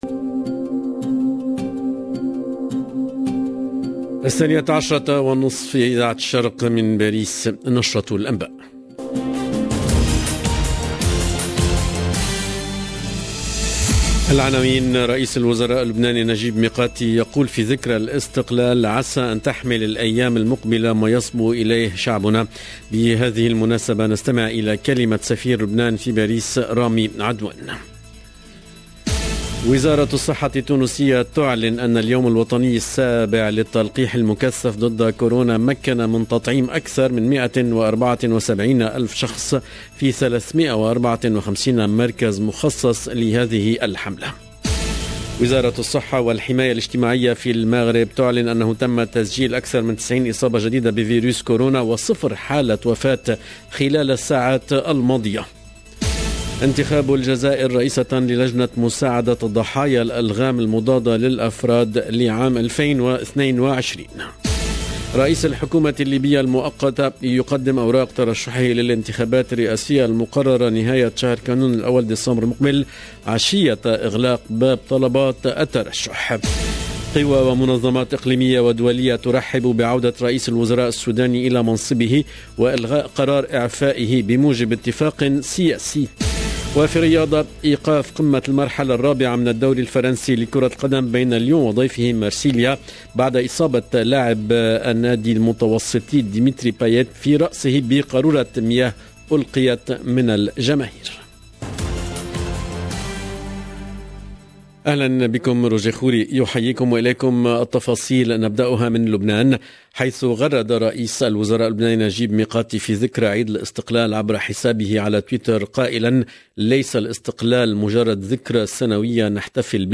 LE JOURNAL EN LANGUE ARABE DE MIDI 30 DU 22/11/21